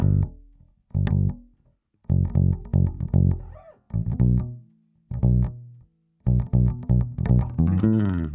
01 Bass PT4.wav